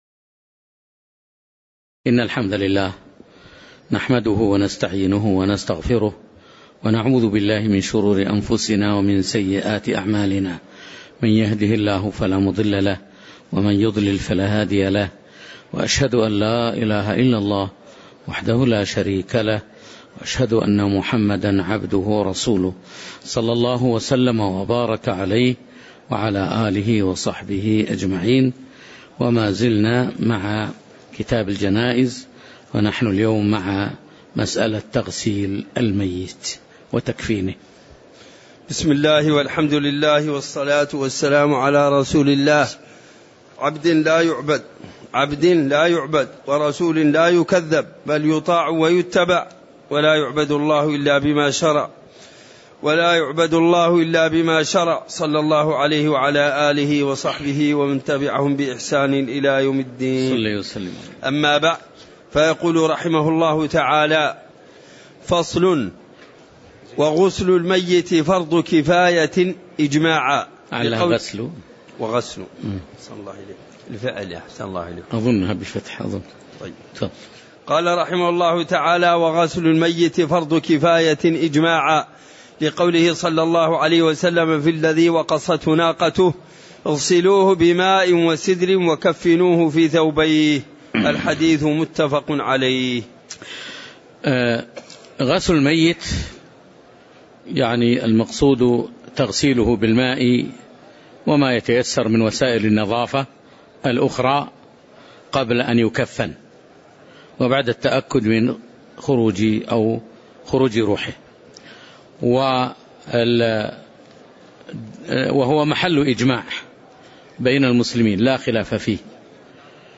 تاريخ النشر ٢٩ ربيع الأول ١٤٣٩ هـ المكان: المسجد النبوي الشيخ